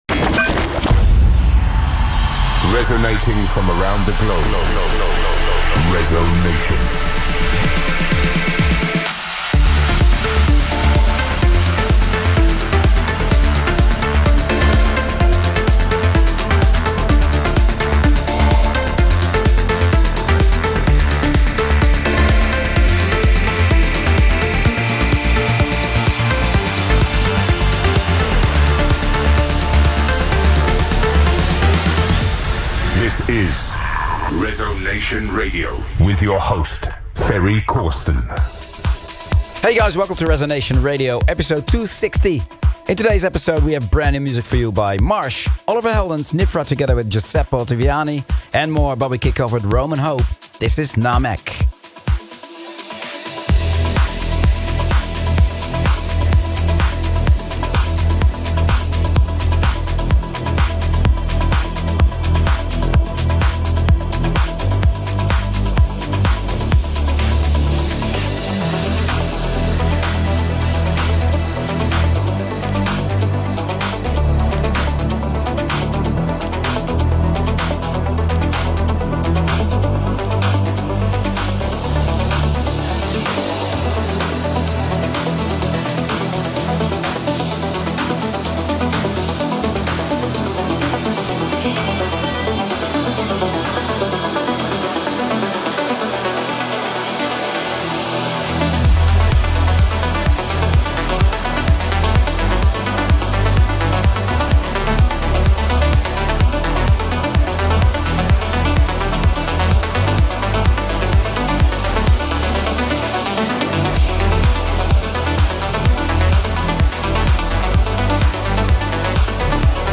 Also find other EDM Livesets, DJ Mixes and Radio Show
progressive, trance, and house vibes